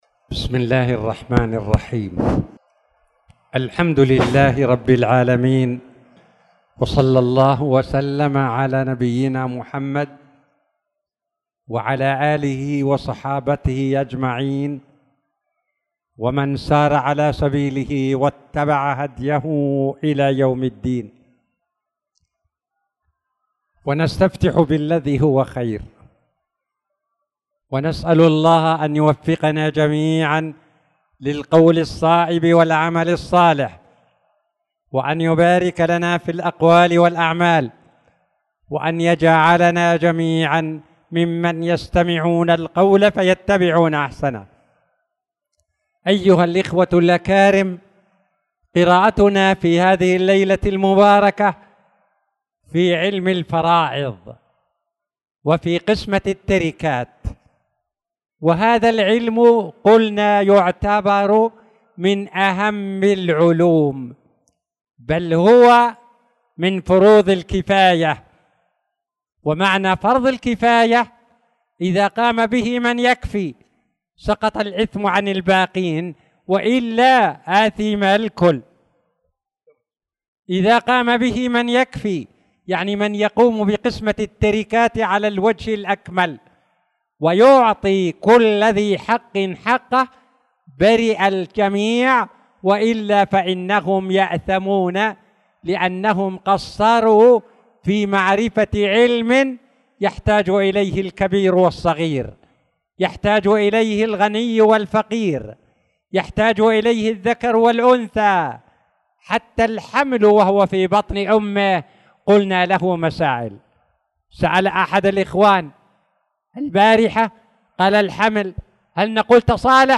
تاريخ النشر ٢١ شوال ١٤٣٧ هـ المكان: المسجد الحرام الشيخ